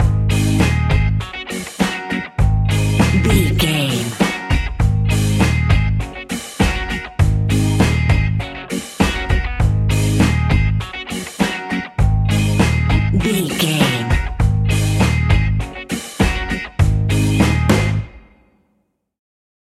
Classic reggae music with that skank bounce reggae feeling.
Uplifting
Ionian/Major
A♭
laid back
off beat
drums
skank guitar
hammond organ
horns